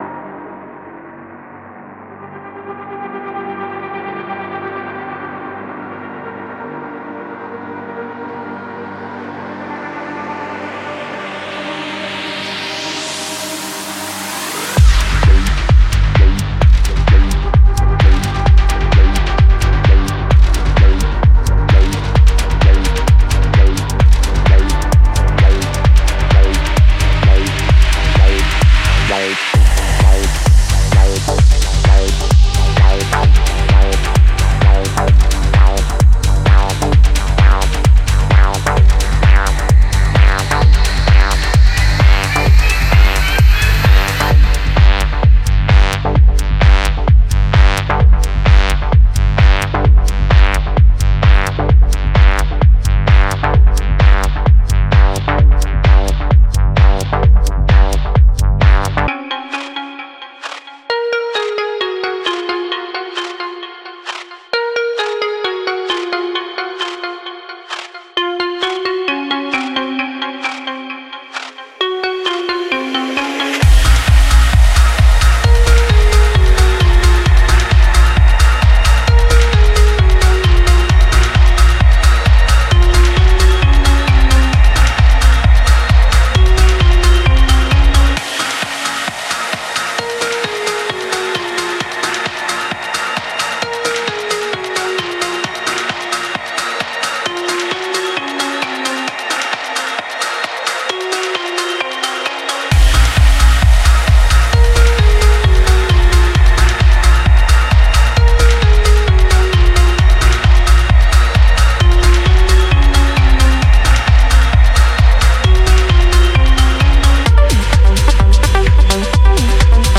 Genre:Techno
デモサウンドはコチラ↓